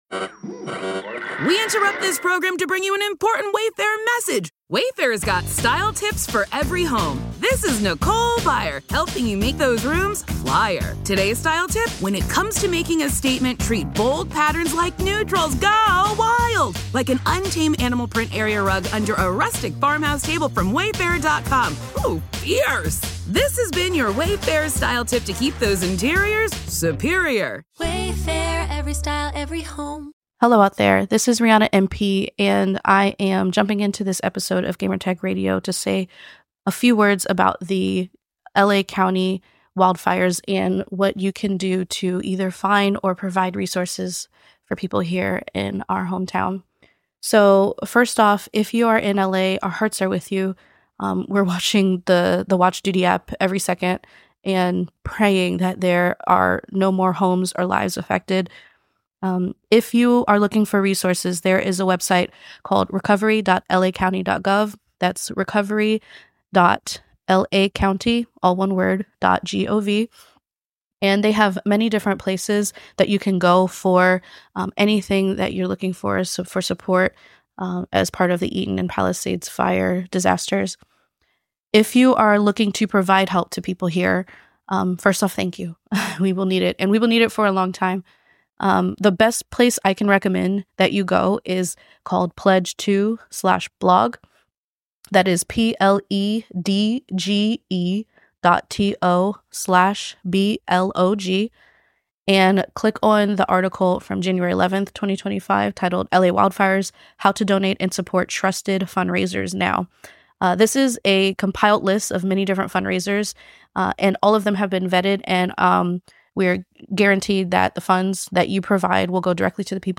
Thick As Thieves Interview with OtherSide Entertainment